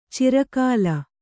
♪ cira kāla